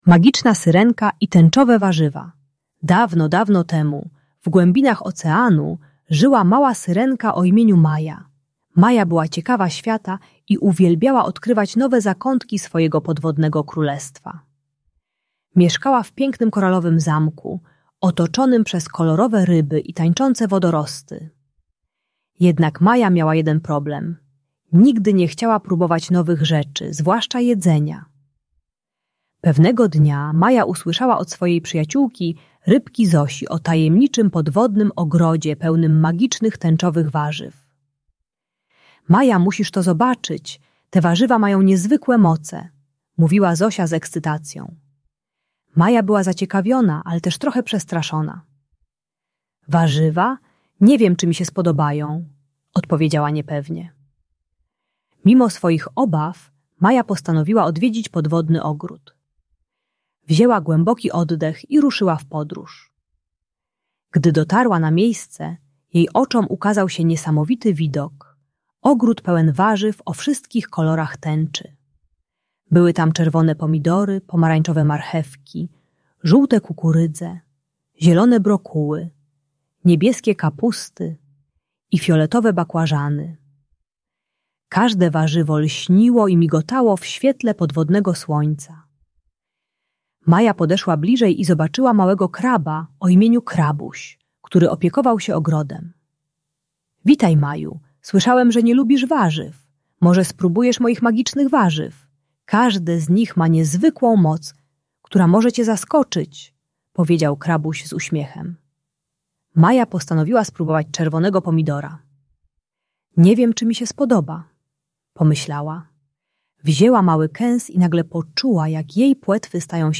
Audiobajka o niejadku pomaga maluchowi przezwyciężyć niechęć do próbowania nowych potraw. Uczy techniki "małego kęsa" - próbowania małych porcji nowego jedzenia bez presji.